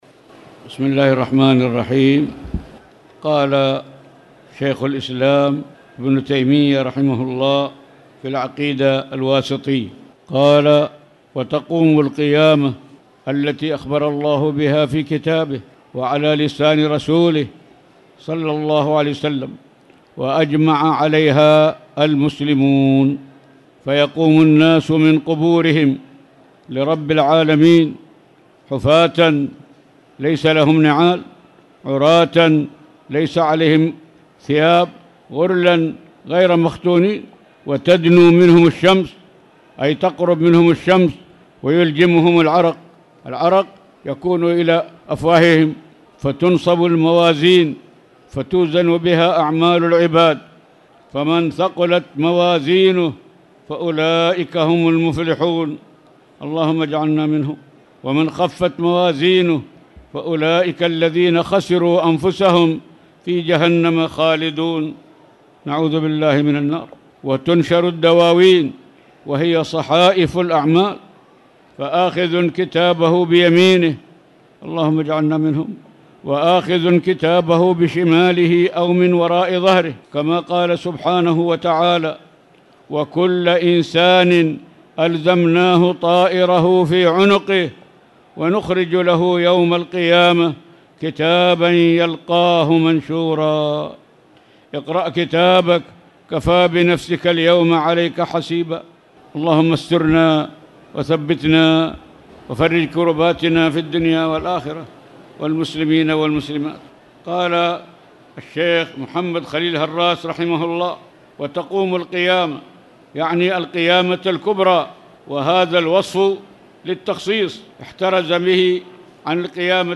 تاريخ النشر ١٦ رمضان ١٤٣٨ هـ المكان: المسجد الحرام الشيخ